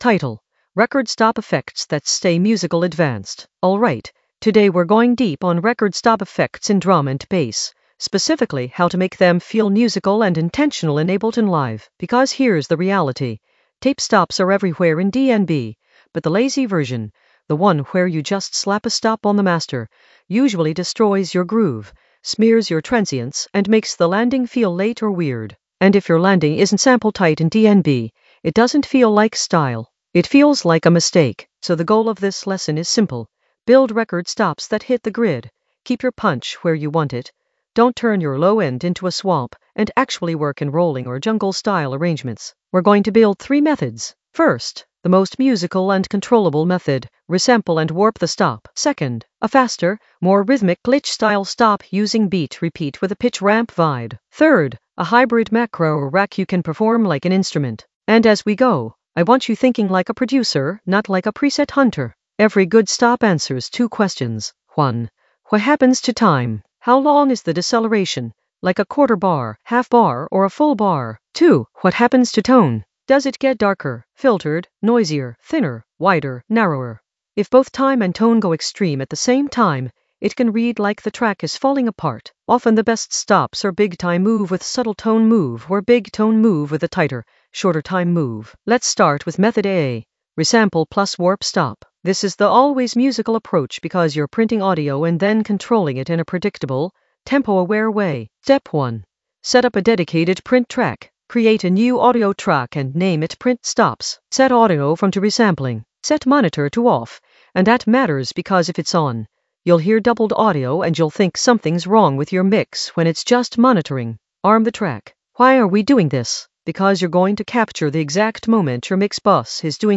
Narrated lesson audio
The voice track includes the tutorial plus extra teacher commentary.
An AI-generated advanced Ableton lesson focused on Record stop effects that stay musical in the FX area of drum and bass production.